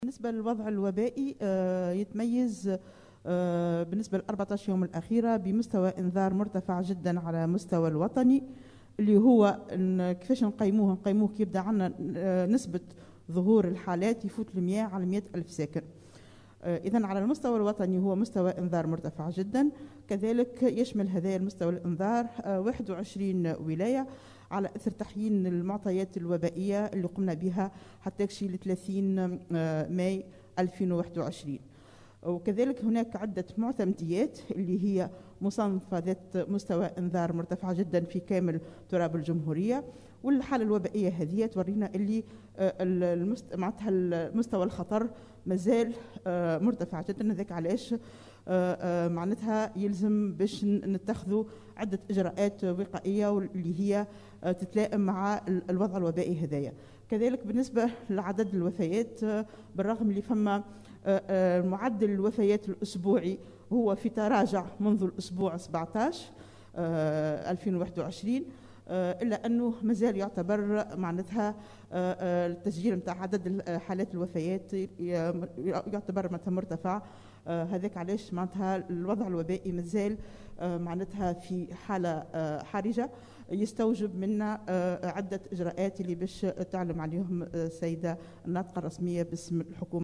وأضافت خلال ندوة صحفية انعقدت، اليوم الجمعة، أنه على الرغم من تراجع معدّل الوفيات الأسبوعي منذ الأسبوع السابع عشر للسنة الحالية إلا أنه يعتبر مرتفع.